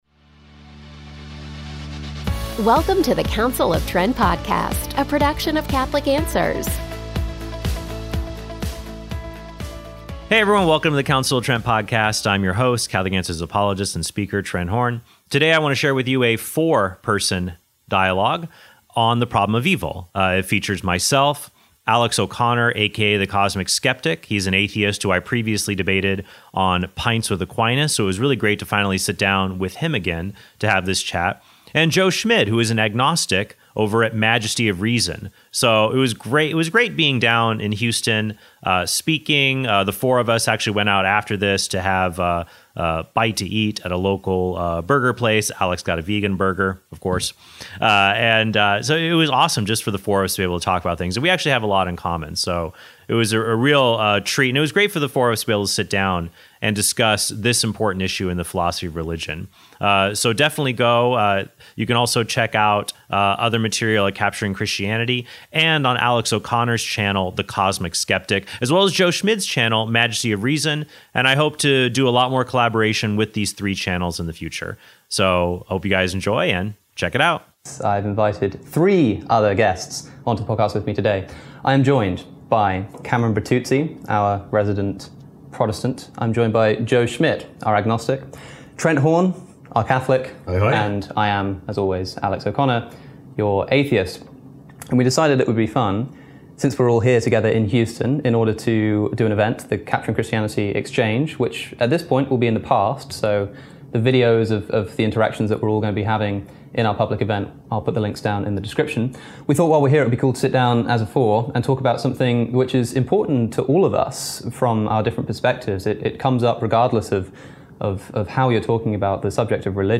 DIALOGUE: Does Evil Disprove God?